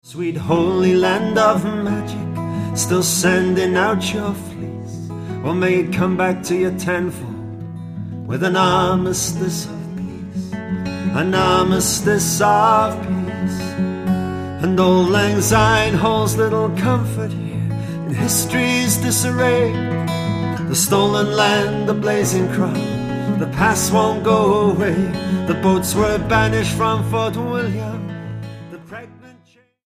STYLE: Roots/Acoustic
Working under his own steam in a home studio